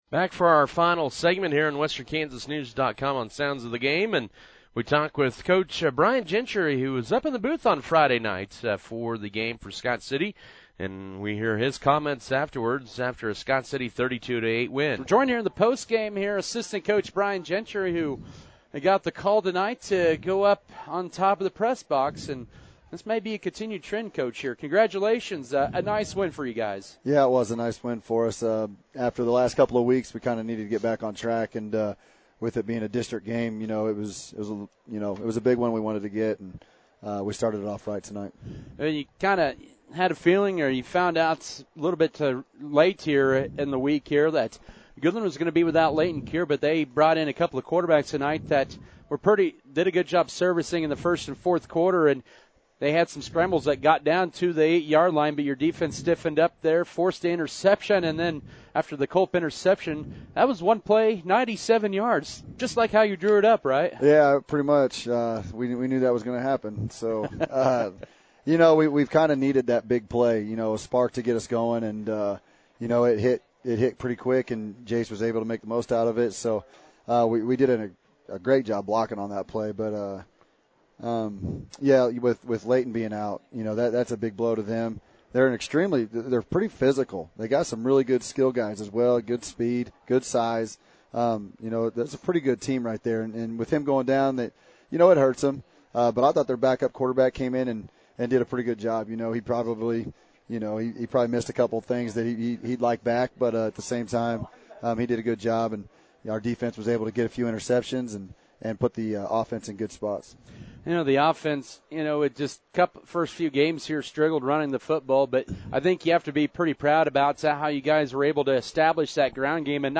Sounds of the Game, featuring Scott City
Here’s a recap of Friday night’s game, in audio fashion.